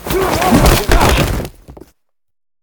tackle3.ogg